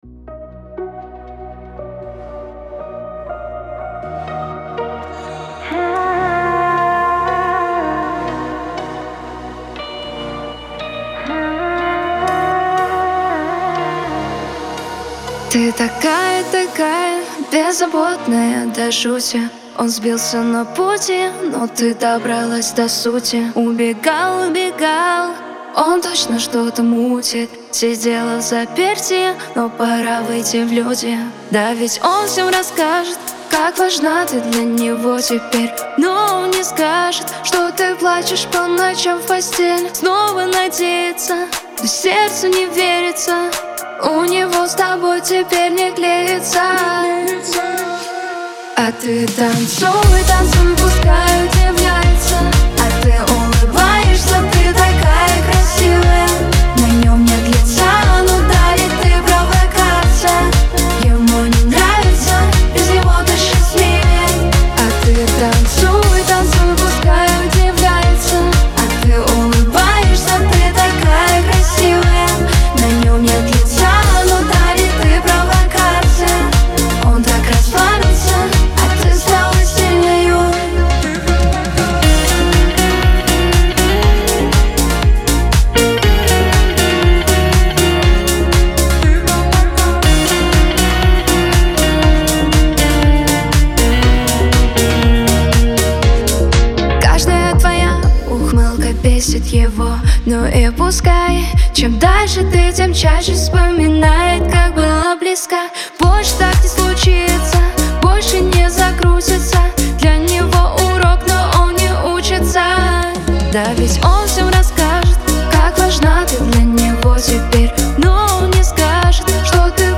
грусть
dance
pop